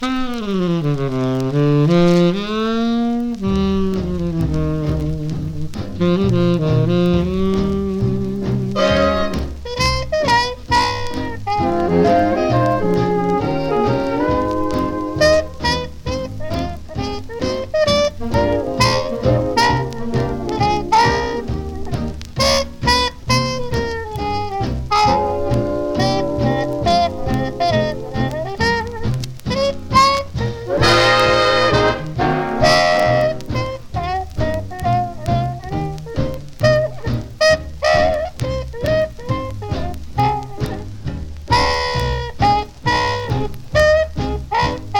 軽やかさとナイトクラブの喧騒を感じるようなミッド・テンポな楽曲がずらり。
Jazz, Big Band, Swing　USA　12inchレコード　33rpm　Mono